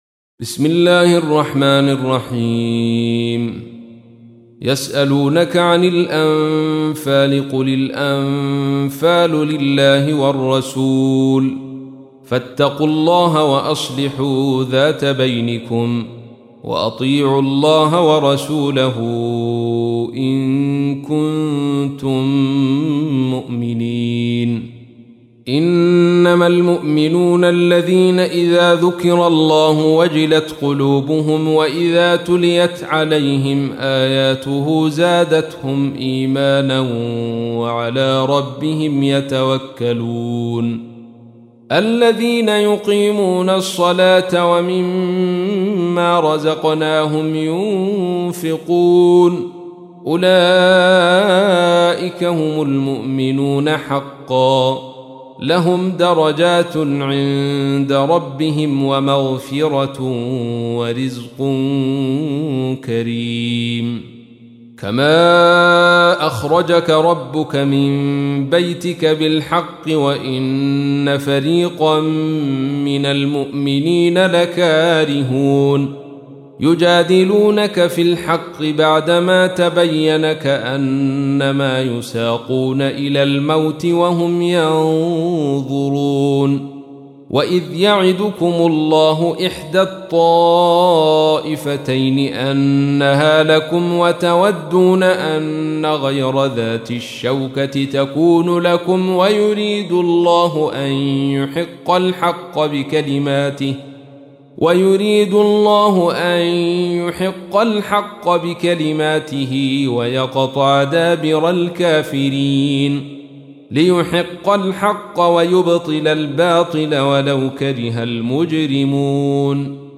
تحميل : 8. سورة الأنفال / القارئ عبد الرشيد صوفي / القرآن الكريم / موقع يا حسين